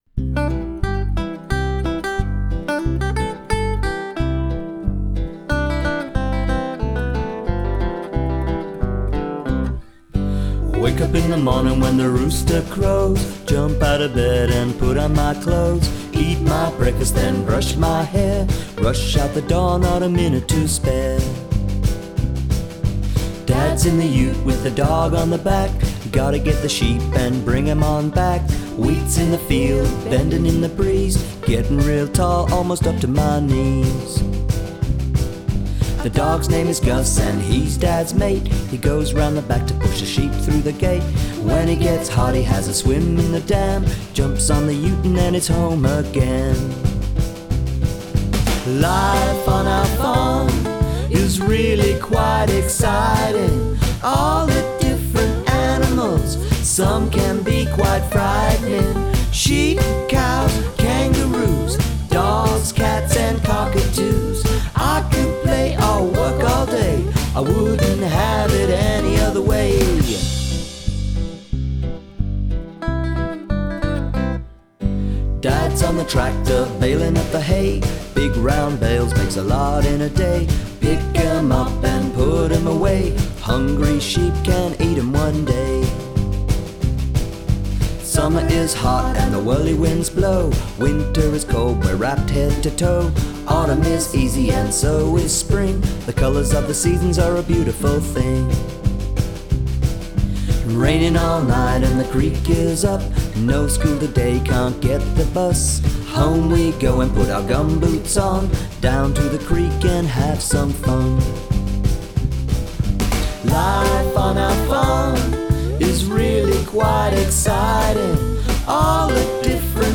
parent friendly children’s album